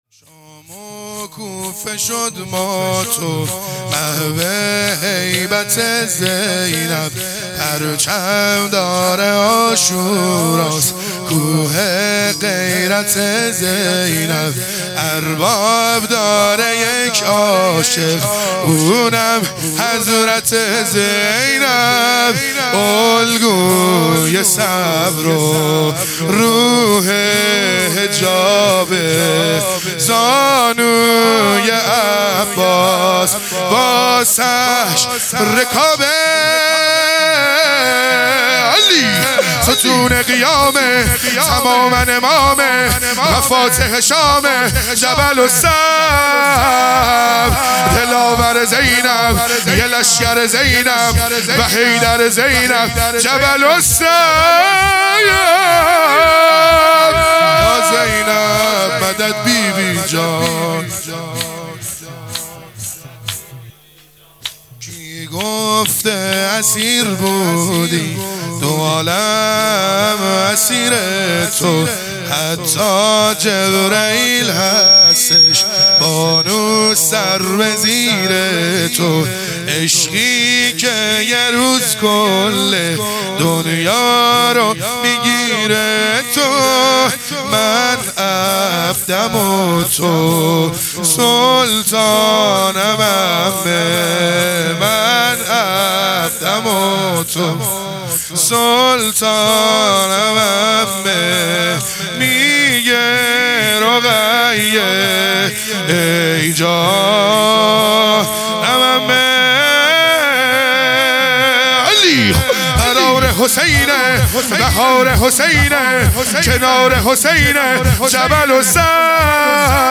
شهادت حضرت زینب (س) | 27 بهمن ماه 1400 | شور | شام و کوفه شد مات و محو
شهادت حضرت زینب (س)